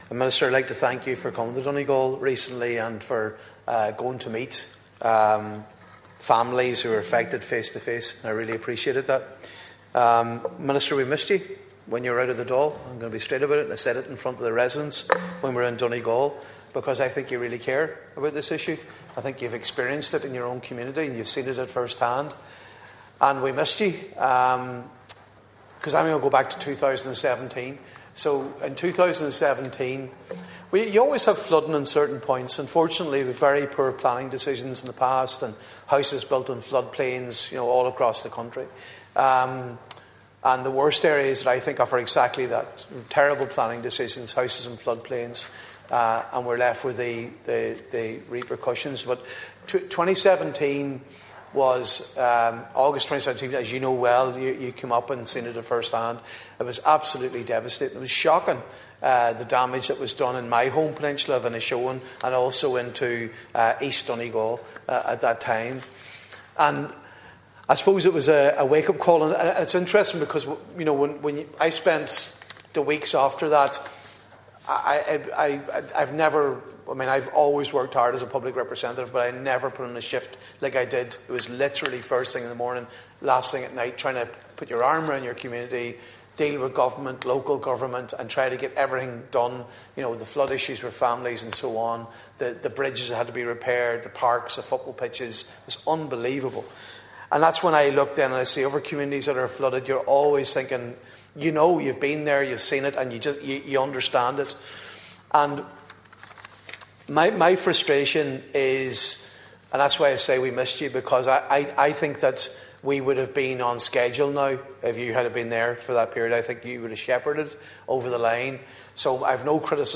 During statements on flooding in Leinster House, Deputy Padraig Mac Lochlainn acknowledged that during his first tenure in the position, Minister Kevin Boxer Moran announced funding for Burnfoot, with a scheme to go to planning shortly.
You can listen to Deputy MacLochlainn’s full contribution here –